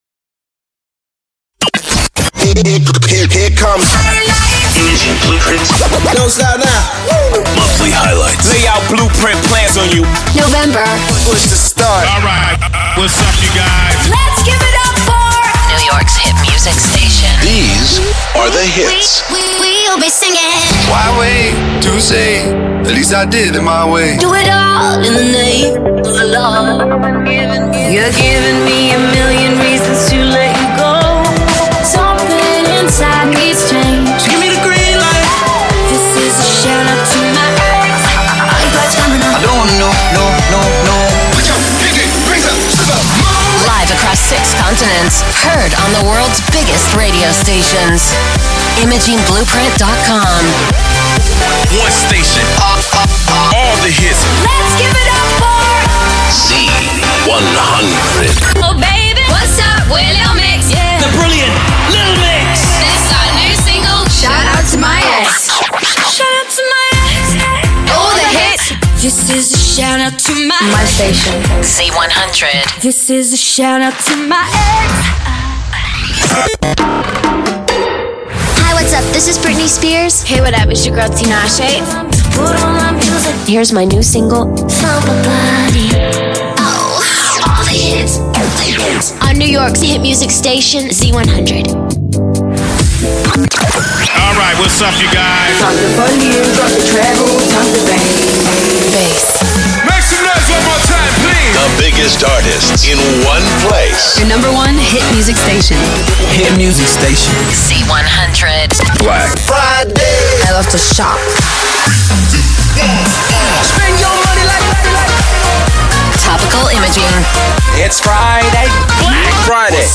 Our client KIIS is used to demonstrate 'IB' production alongside the world famous Z100.
It features an innovative, online database containing thousands of audio files including sound design, music beds, artist-imaging workparts, vocal work parts, topical elements and much much more!